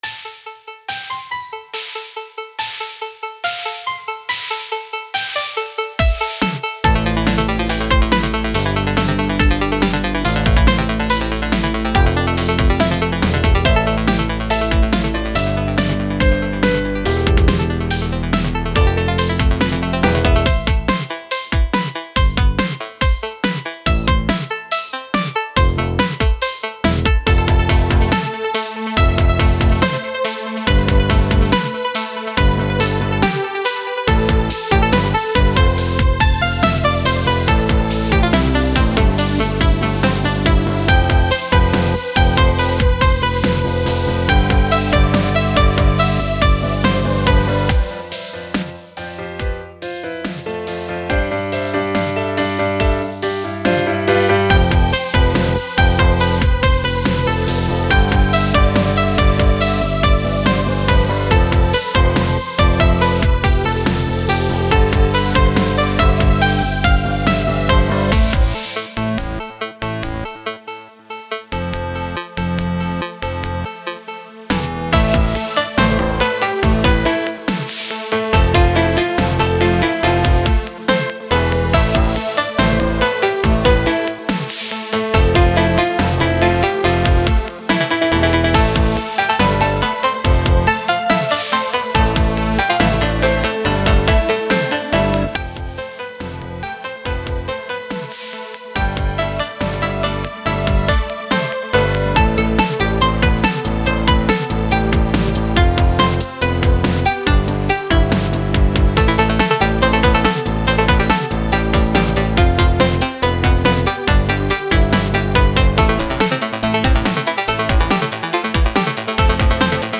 A mix of piano, chiptunes, and electronic instruments.